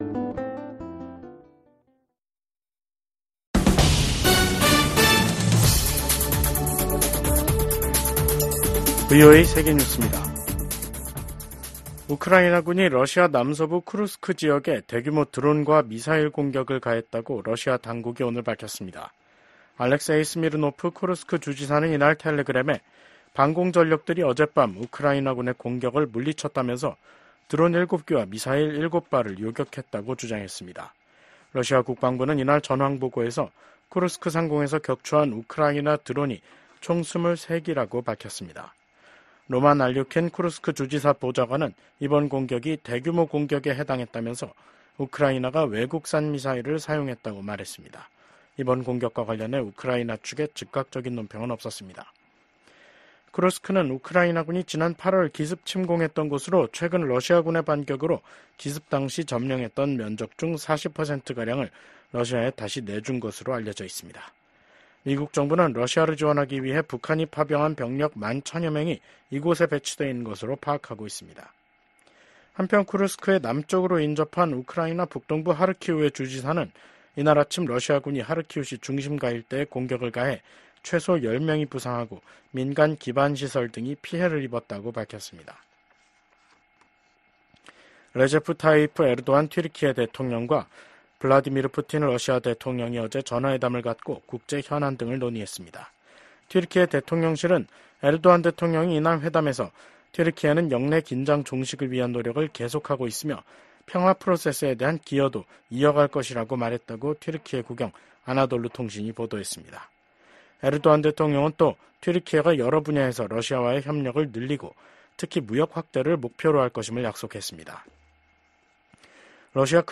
VOA 한국어 간판 뉴스 프로그램 '뉴스 투데이', 2024년 11월 25일 2부 방송입니다. 러시아 고위 당국자가 한국이 우크라이나에 살상무기를 공급하면 강력 대응하겠다고 경고했습니다. 미국 백악관 고위 관리는 북한과 러시아의 군사적 관계 강화가 차기 행정부에도 쉽지 않은 도전이 될 것이라고 전망했습니다. 미국은 북한이 7차 핵실험 준비를 마치고 정치적 결단만 기다리고 있는 것으로 평가하고 있다고 국무부 당국자가 말했습니다.